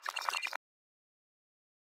Percs
TS - PERC (10).wav